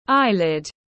Eyelid /ˈaɪ.lɪd/